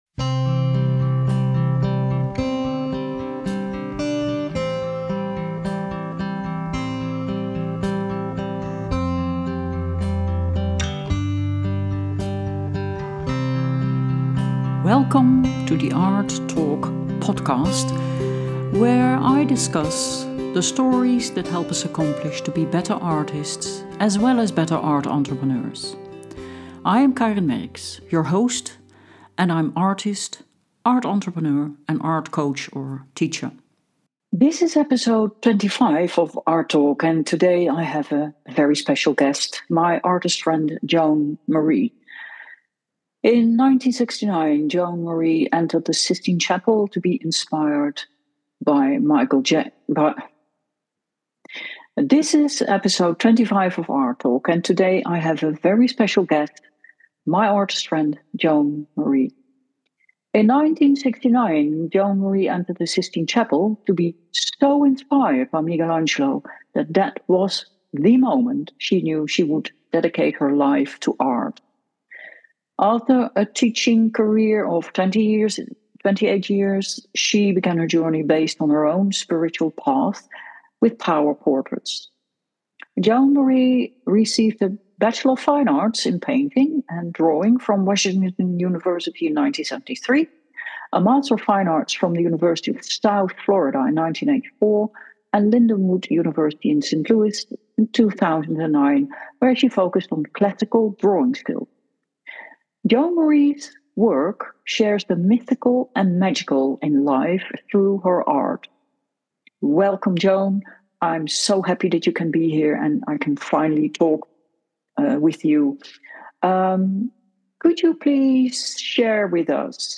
Art Talk – Interview